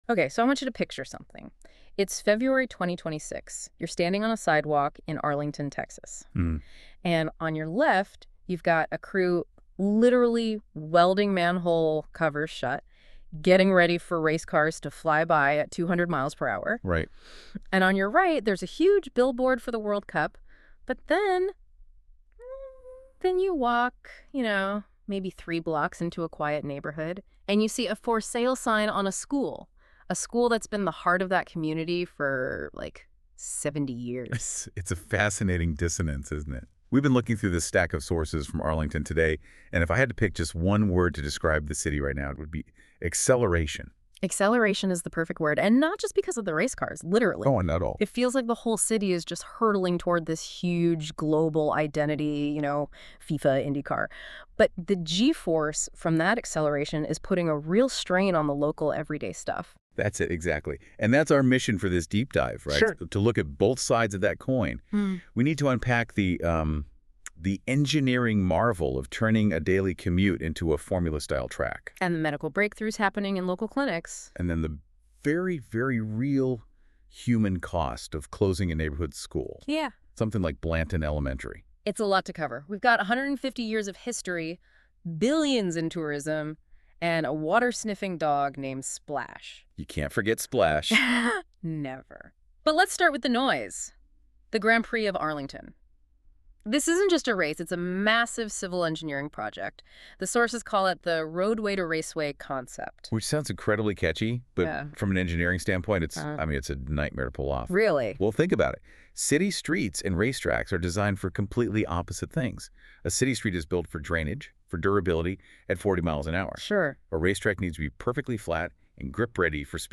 Click Below to listen in on a conversation about Arlington Today February 2026 Issue.